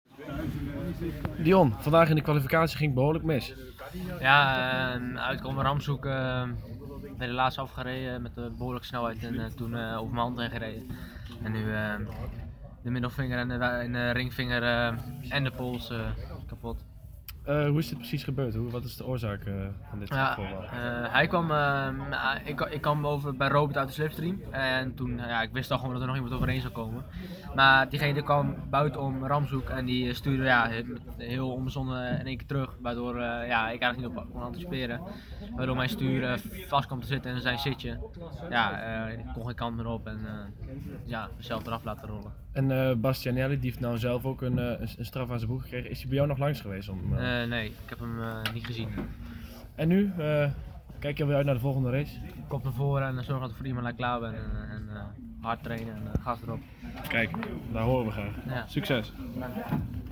Zaterdagmiddag aan het einde van de dag